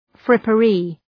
frippery